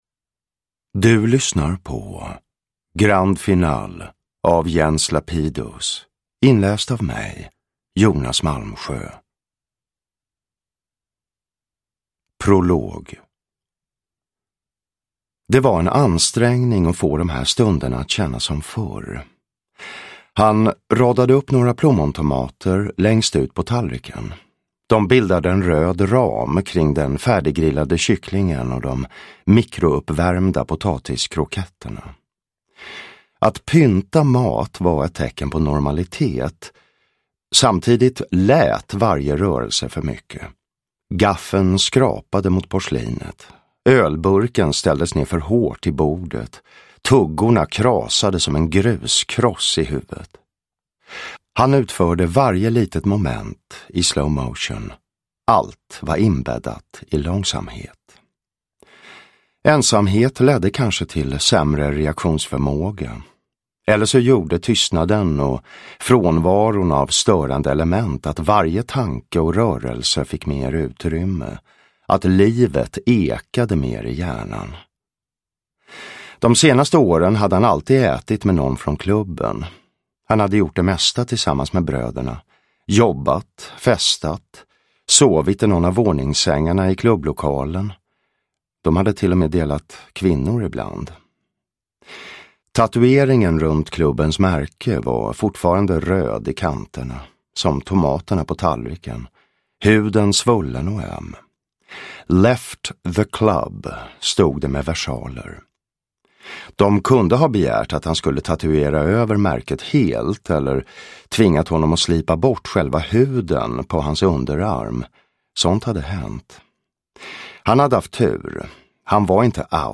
Grand final – Ljudbok
Uppläsare: Jonas Malmsjö